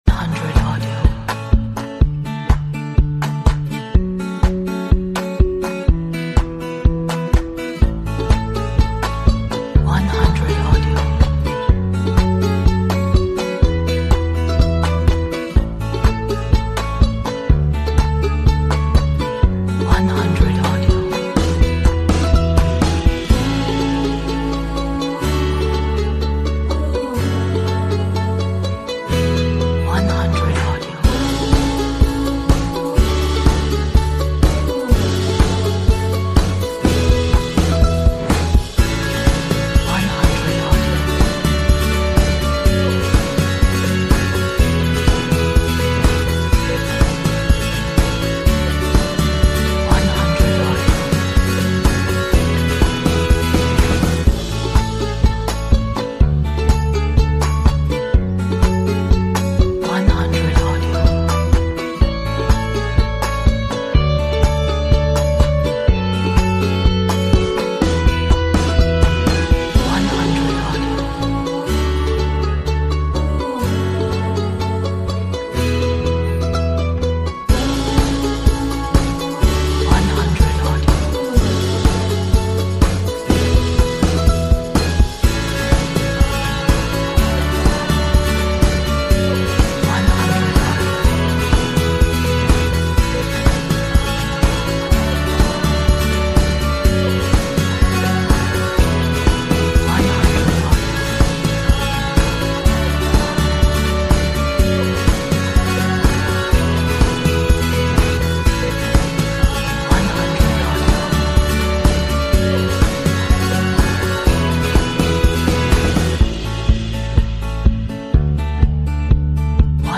is uplifting and upbeat acoustic indie folk track